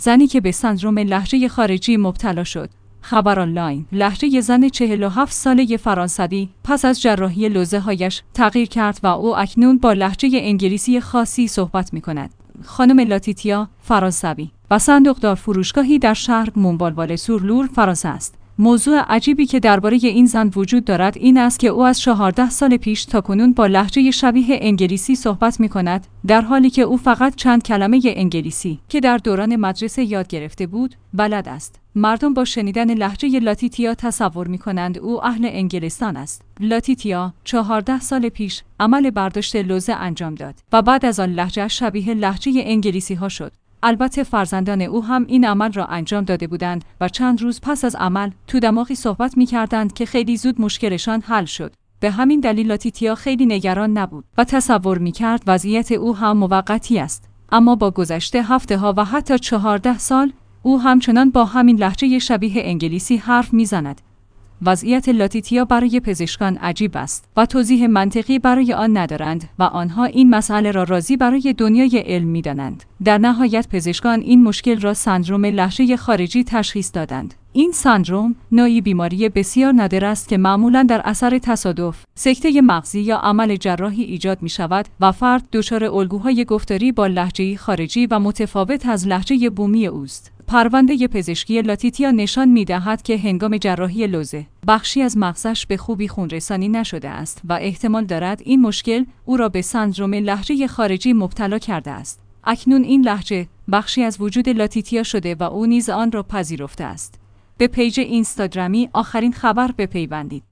زنی که به سندرم لهجه خارجی مبتلا شد